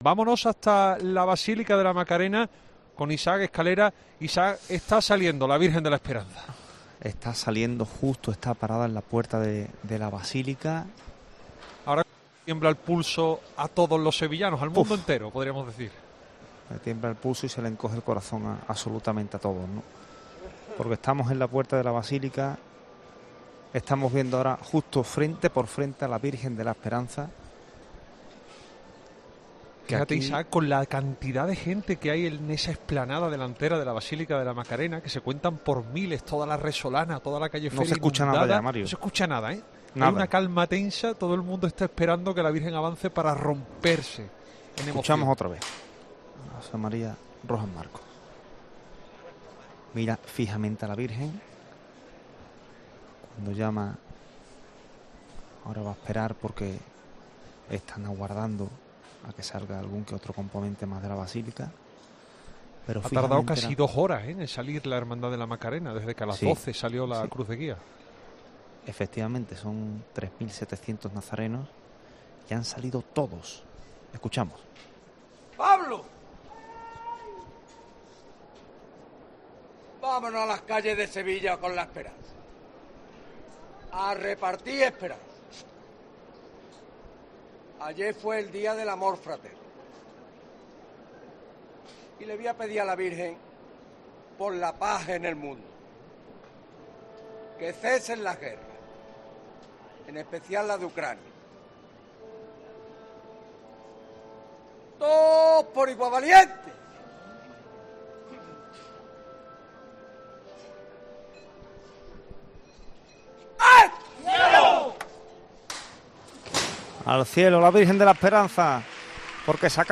La Virgen de la Macarena sale a las 01.49h de la madrugada: “Tiembla el pulso a todos los sevillanos y se le encoge el corazón”. En los alrededores de la basílica no se escucha nada, la calma es tensa tras casi dos horas desde que comenzara la procesión.
Tras la salida de la Señora de Sevilla con toda la candelería encendida, suena el Himno Real interpretado por la banda del 'Carmen de Salteras'.